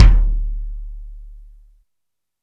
INSKICK16 -R.wav